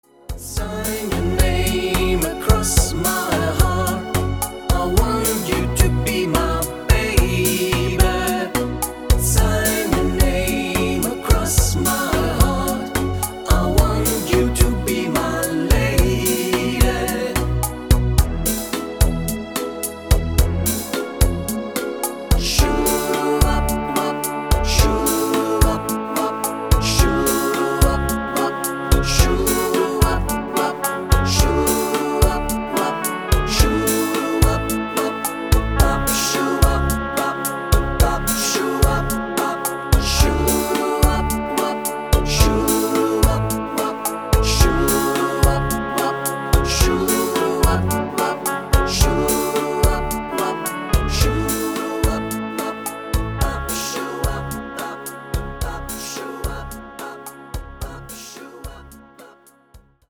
Super Standardtanz